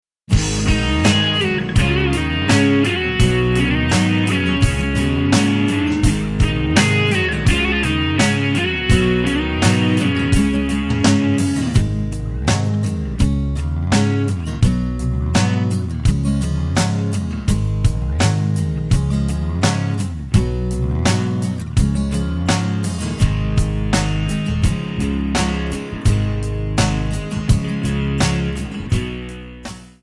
Dm
Backing track Karaoke
Pop, Rock, 2000s